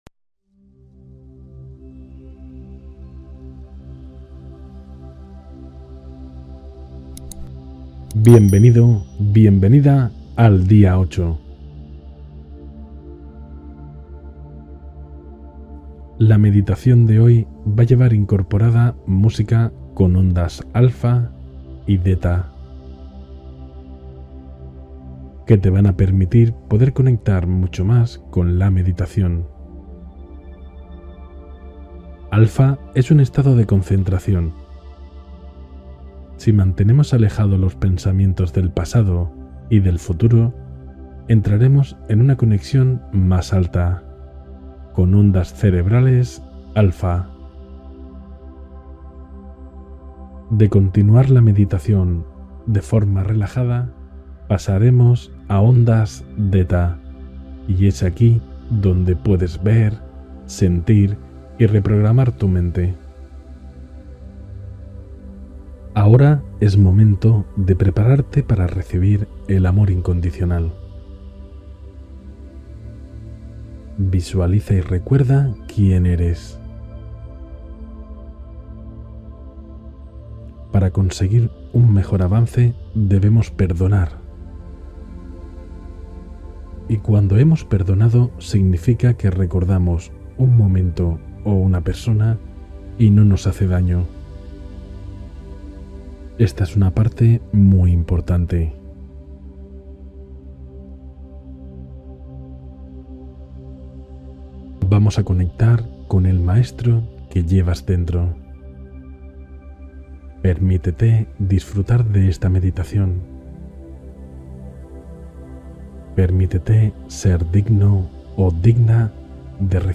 Meditación hacia el Yo Interior: Día 8 del Reto de 21 Días